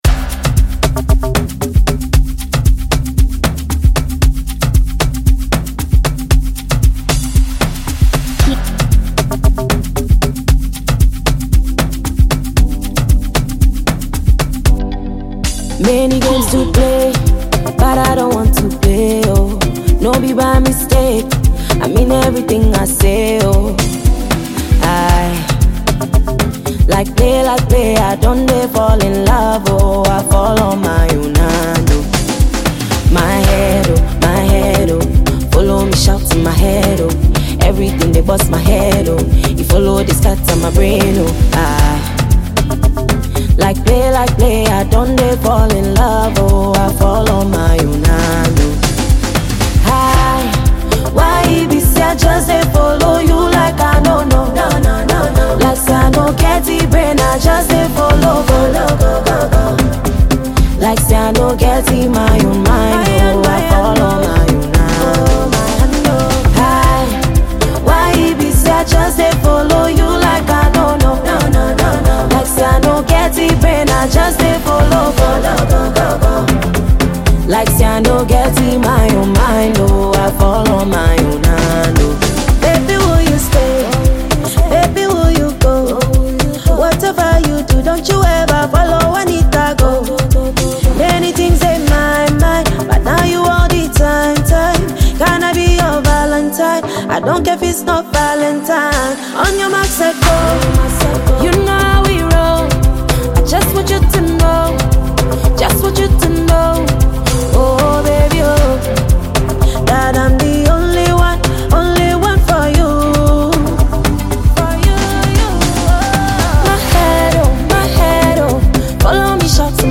Ghanaian female singer and songwriter